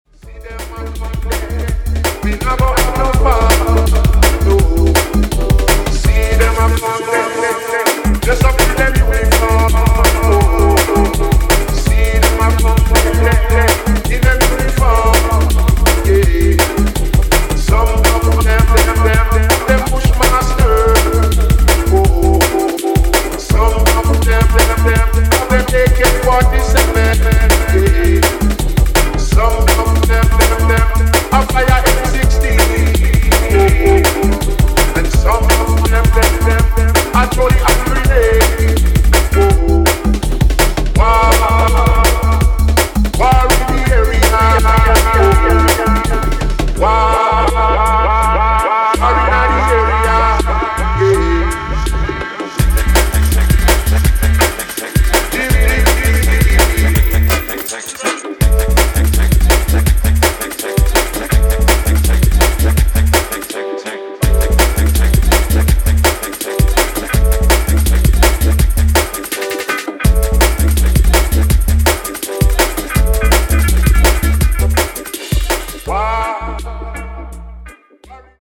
Starting off with a dancehall/jungle ting